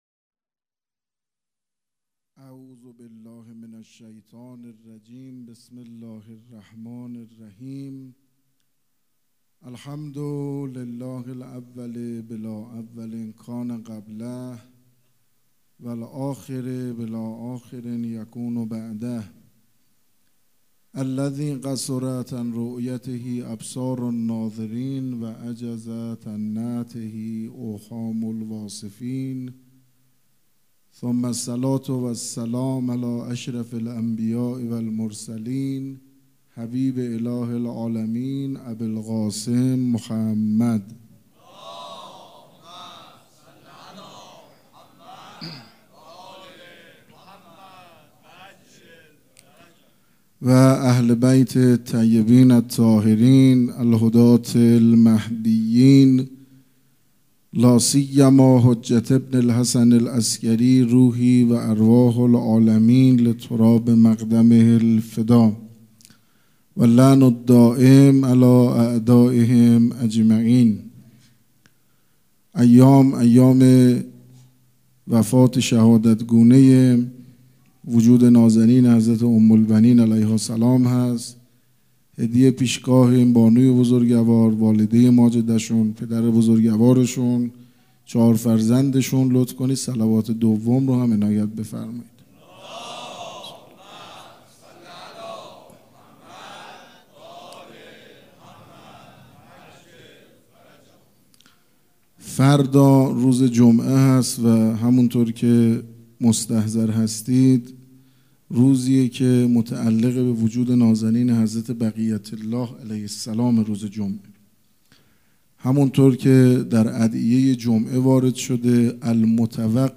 سخنرانی
سخنرانی.mp3